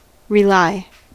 Ääntäminen
Ääntäminen US : IPA : [ɹɪˈlaɪ]